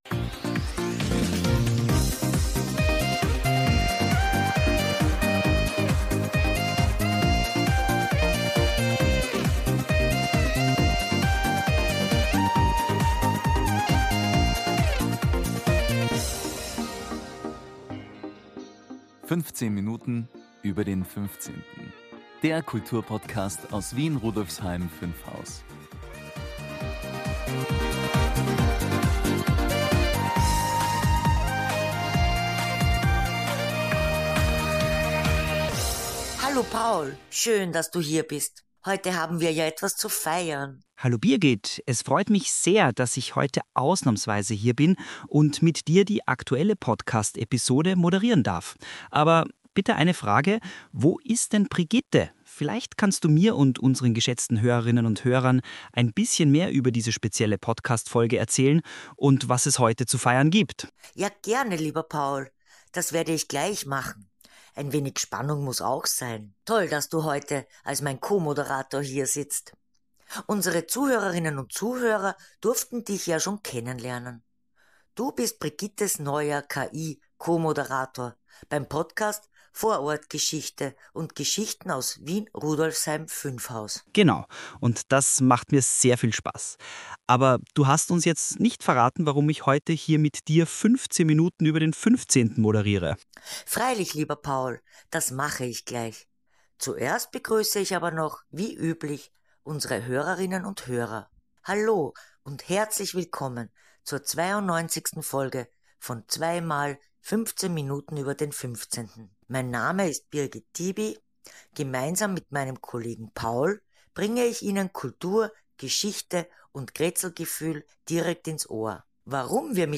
Hinweis: Text und Audio wurden zum Teil mithilfe von KI-Tools erstellt und von unserem Team überprüft und überarbeitet.